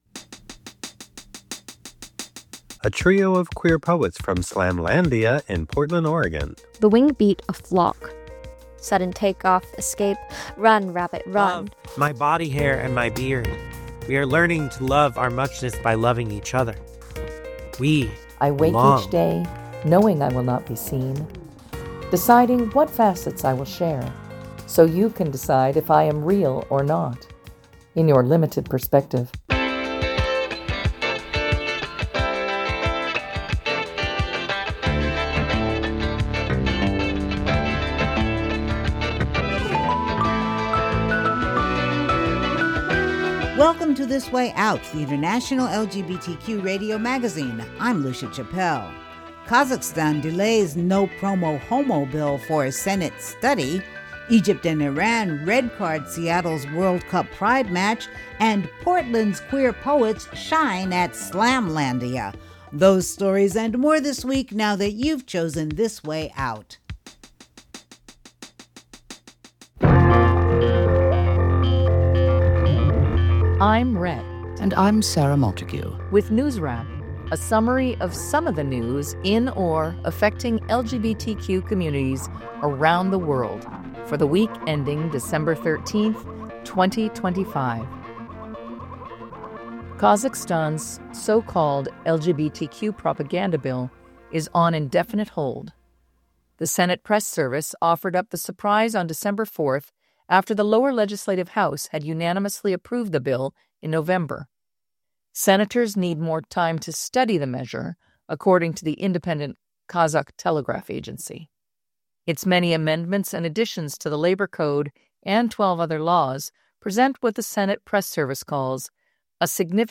Subtitle: The International LGBTQ radio magazine wk of12-15-25 Program Type
Cameo: Budapest Mayor Gergely Karácsony.